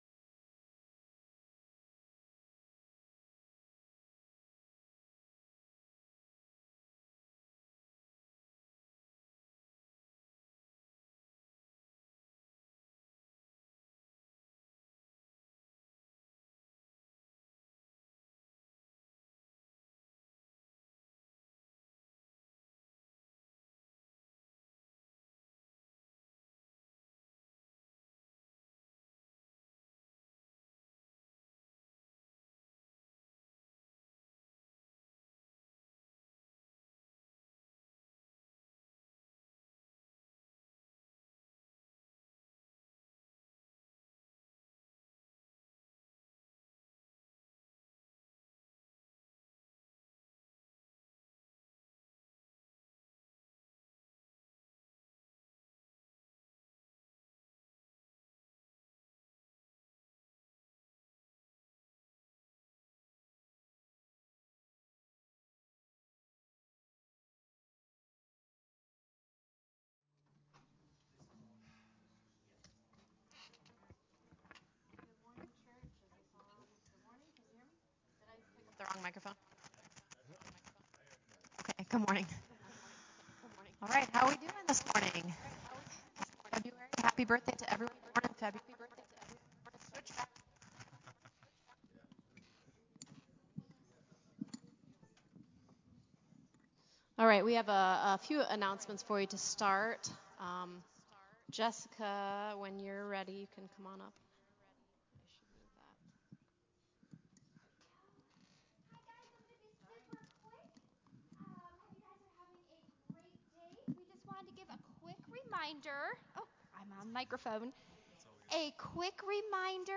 Our worship team bring us another great set of songs.